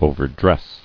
[o·ver·dress]